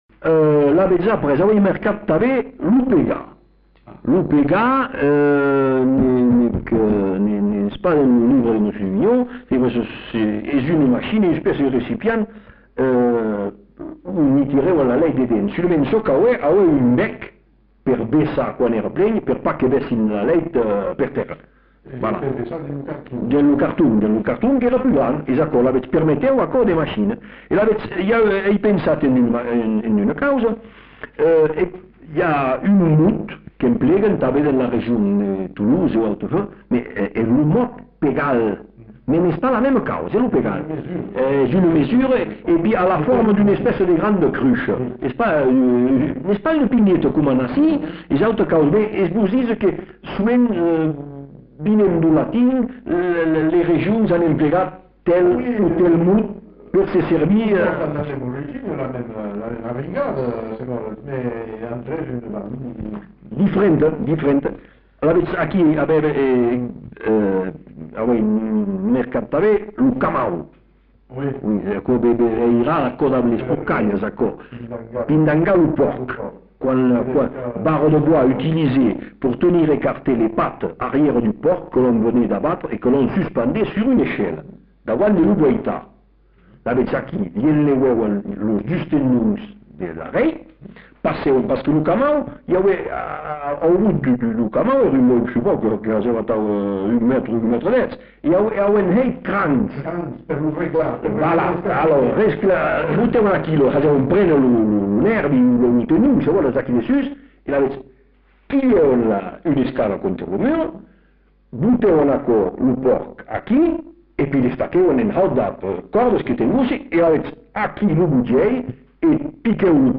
Aire culturelle : Bazadais
Lieu : Bazas
Genre : témoignage thématique